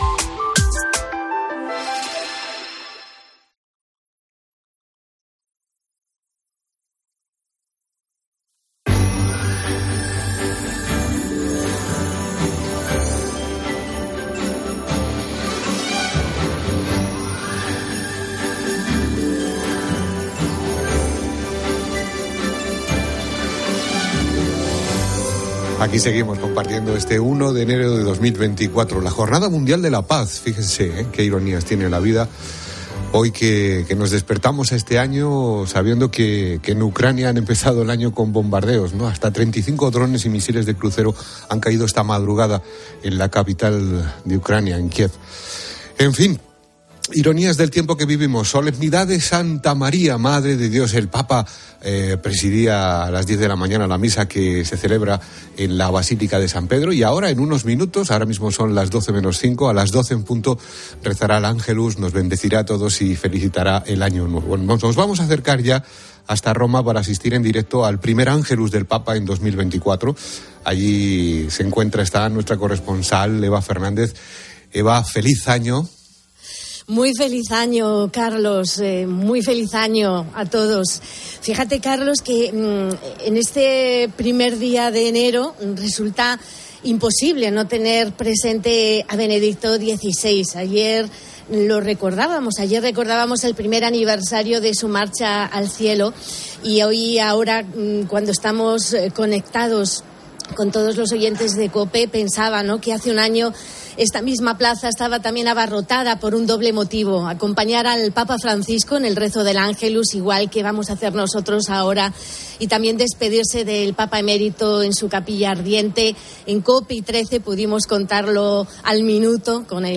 Santa Misa